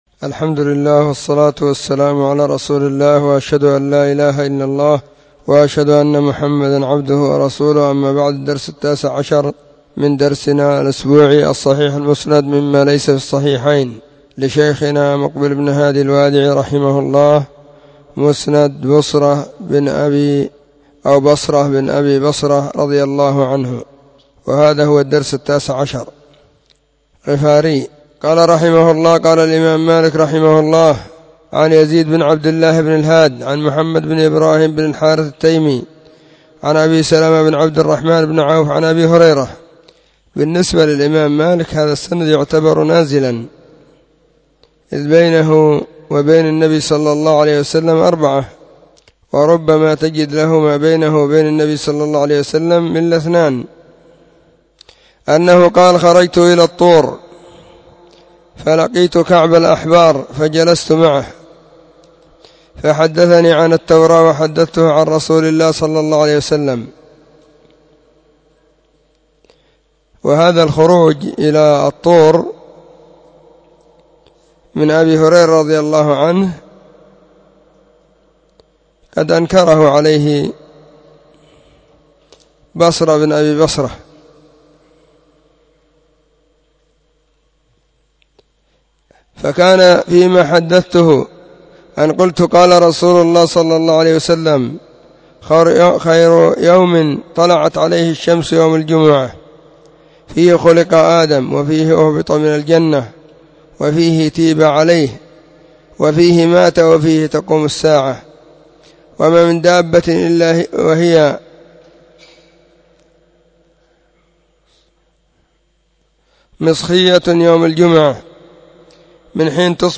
خميس -} 📢مسجد الصحابة – بالغيضة – المهرة، اليمن حرسها الله.